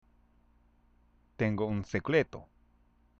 （テンゴ　ウン　セクレト）